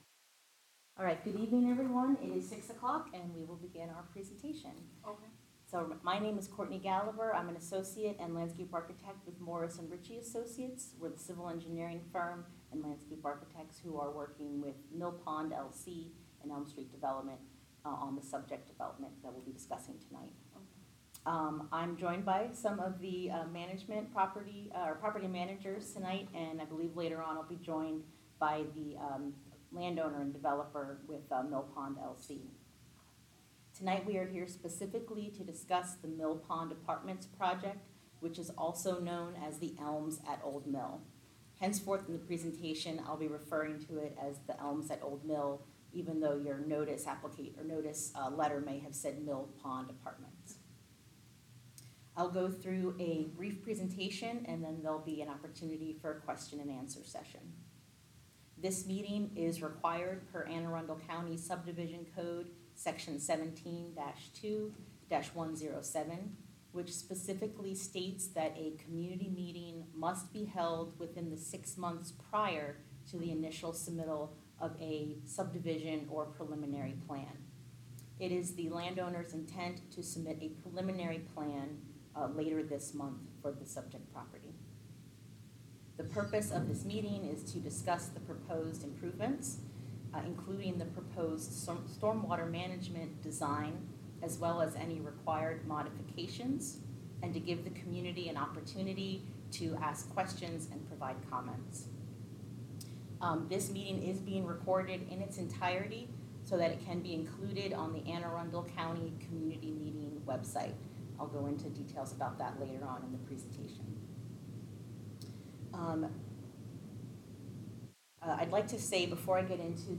Mill Pond Apartments (Elms at Old Mill) Presub Community Meeting